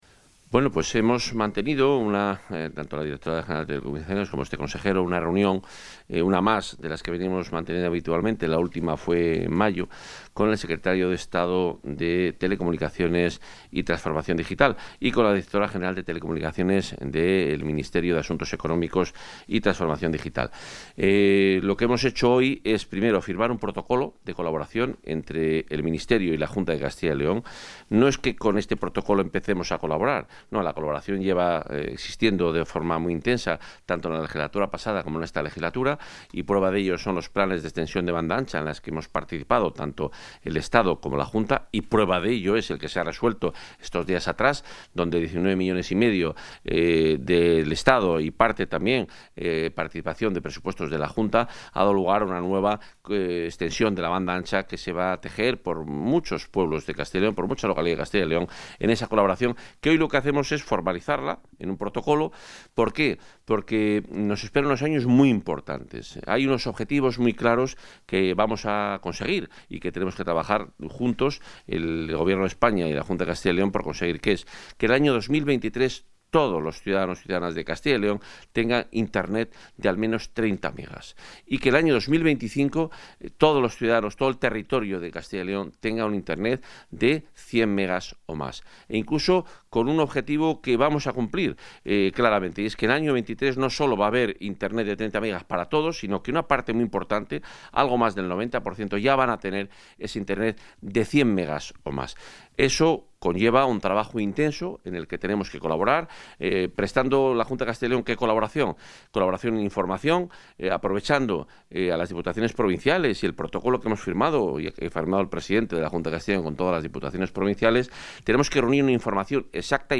Valoración del consejero de Fomento y Medio Ambiente.